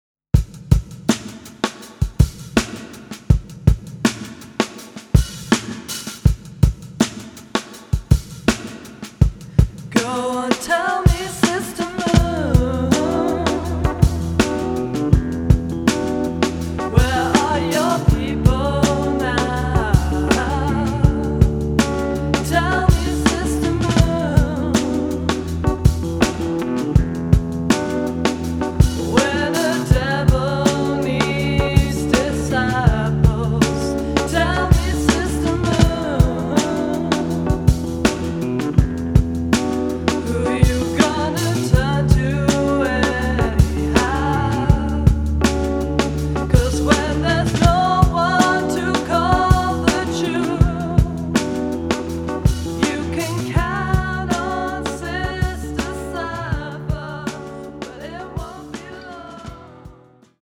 To the ethereal acoustics of:-
Recorded at the Observatory